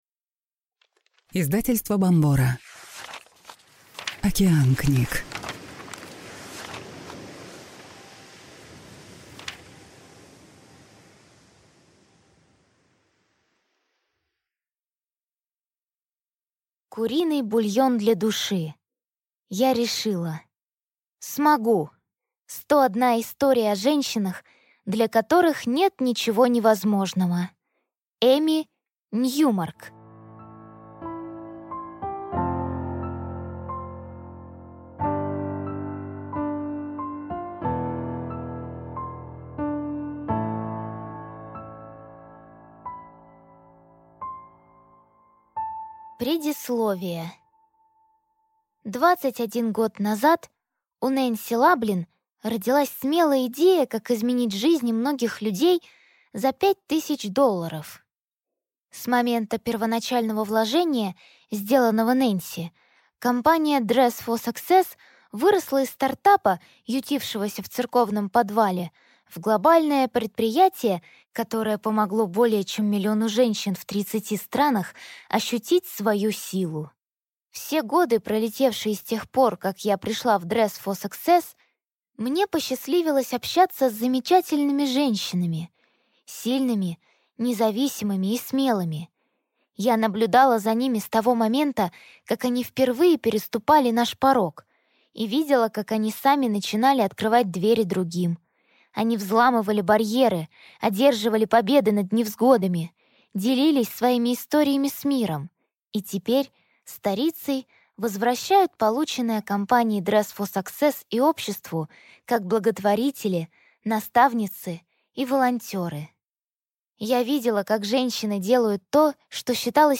Аудиокнига Куриный бульон для души. Я решила – смогу! 101 история о женщинах, для которых нет ничего невозможного | Библиотека аудиокниг